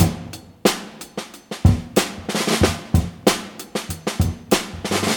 • 93 Bpm Drum Groove E Key.wav
Free drum loop sample - kick tuned to the E note.
93-bpm-drum-groove-e-key-Ddj.wav